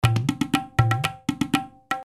Tabla 2
Tag: 120 bpm Ethnic Loops Tabla Loops 344.70 KB wav Key : Unknown